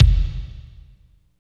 29.09 KICK.wav